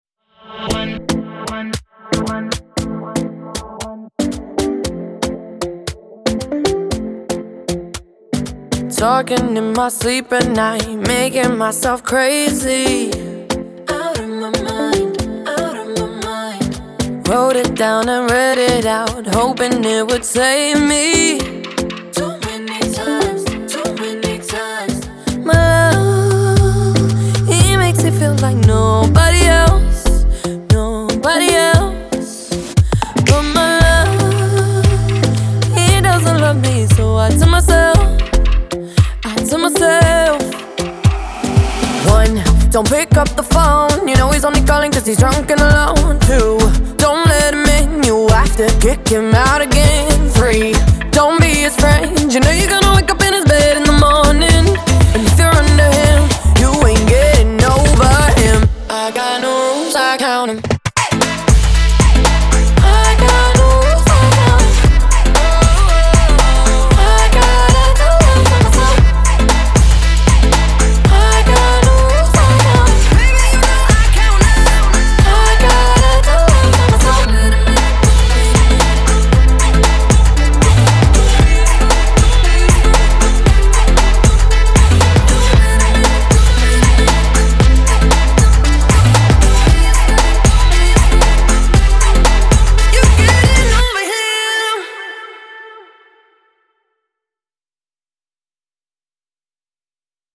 BPM116